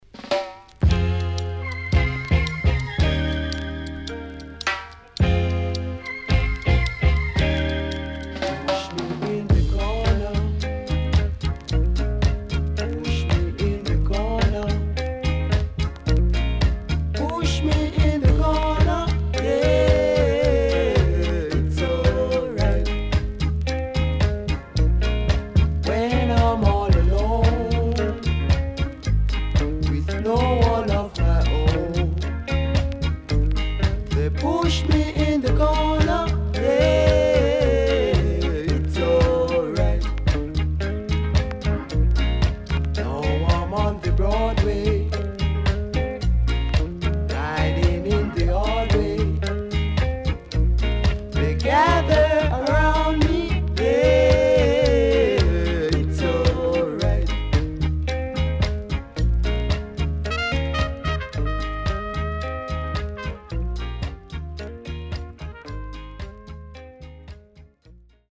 いろいろなアーティストにCoverされている名曲 Good Vocal & Inst